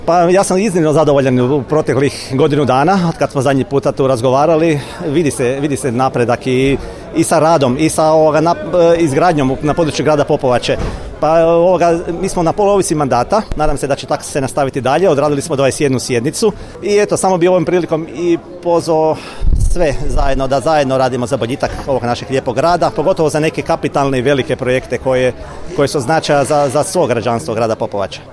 Svečanom sjednicom Gradskog vijeća, Popovača je u srijedu, 21. lipnja 2023. godine proslavila Dan grada i blagdan svoga zaštitnika, sv. Alojzija Gonzage.
Predsjednik Gradskog vijeća Grada Popovače Saša Jagarčec